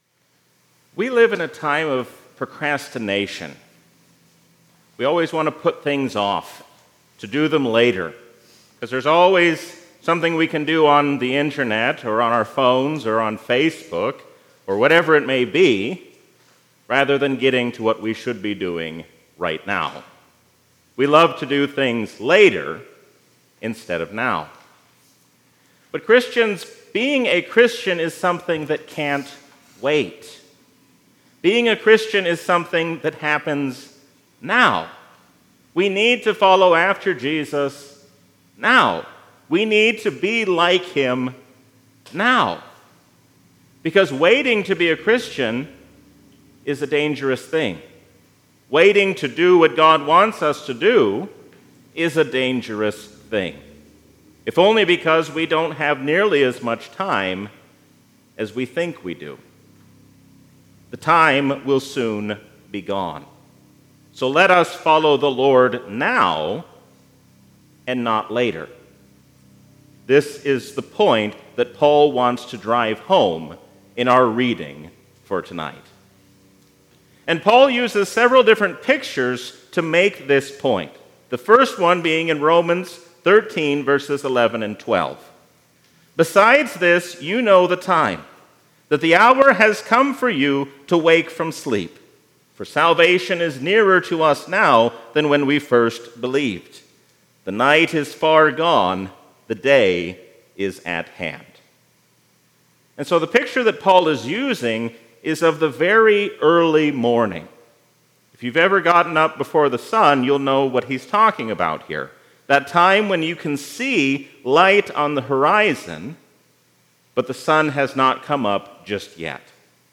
A sermon from the season "Trinity 2022."